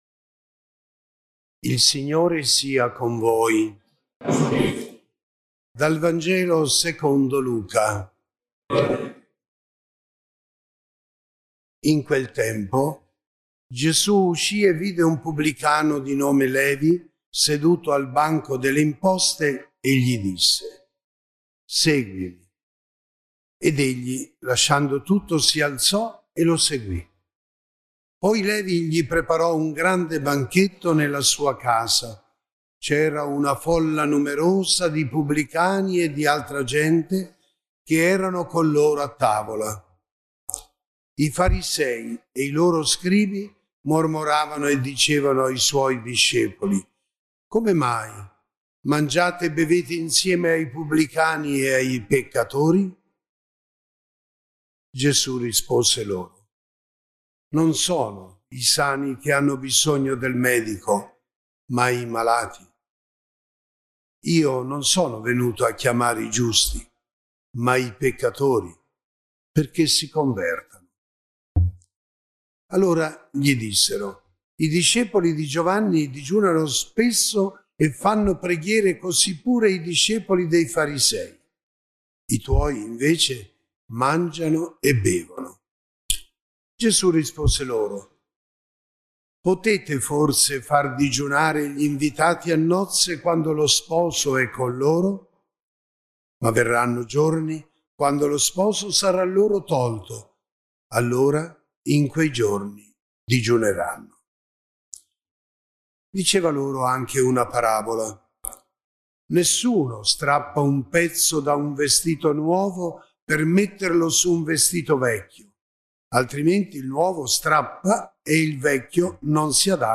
Santità, salvezza, fragilità umana. Sono stati questi i temi su cui si è soffermato il Vescovo Armando guidando, lunedì 6 marzo nella Basilica di San Paterniano, il secondo Quaresimale animato dalla Cappella Musicale del Duomo di Fano